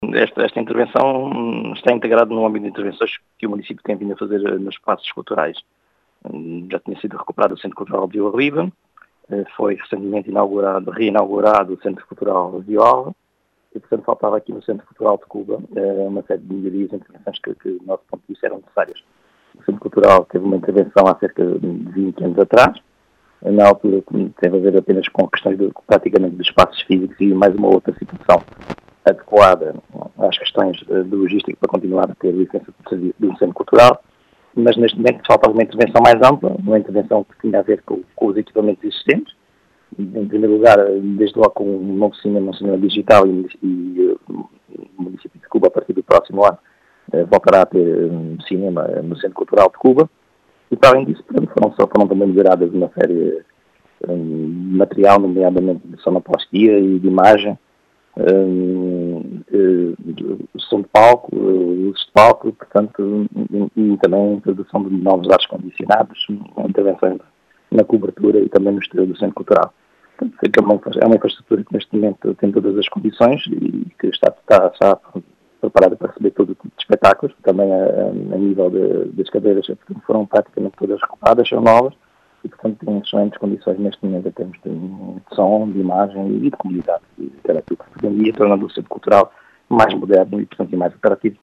As explicações são do presidente da Câmara Municipal de Cuba, João Português, que realçou esta “ampla” intervenção neste equipamento municipal.